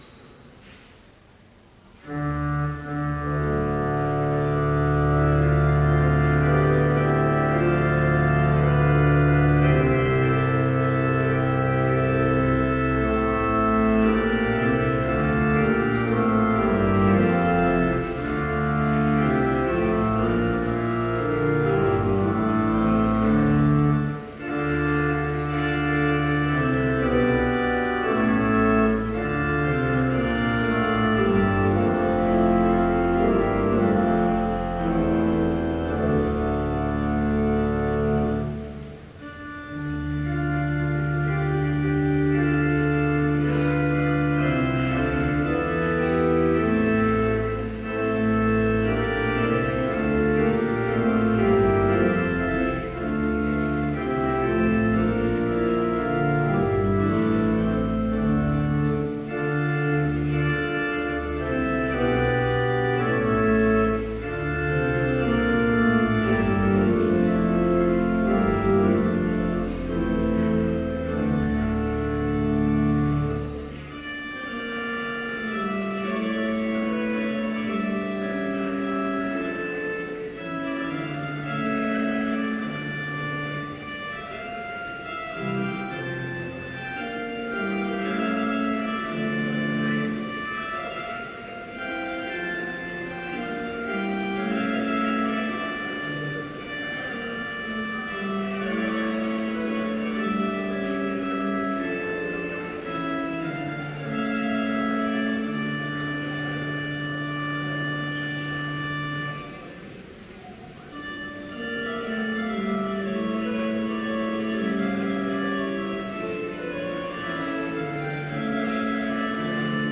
Complete service audio for Chapel - April 29, 2014